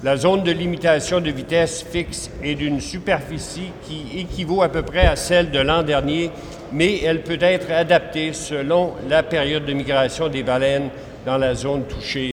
Le ministre des Transports Marc Garneau: